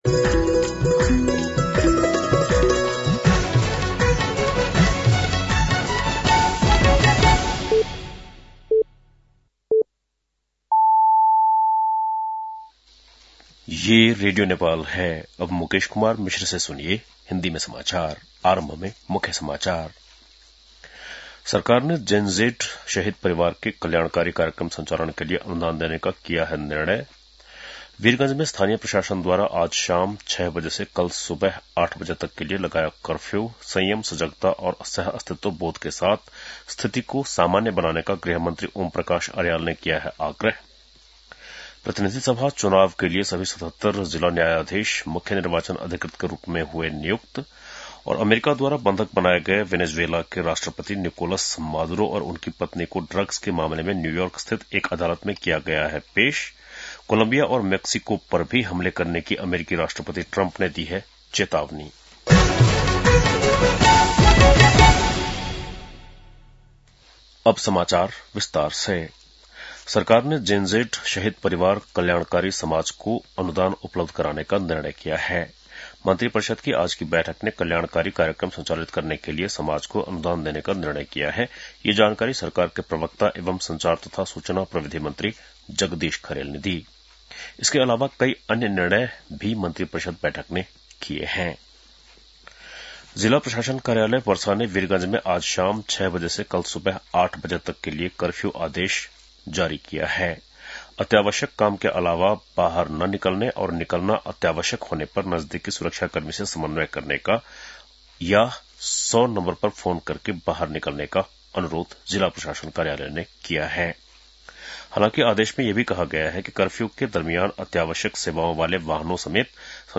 बेलुकी १० बजेको हिन्दी समाचार : २१ पुष , २०८२
10-PM-Hindi-NEWS-9-21.mp3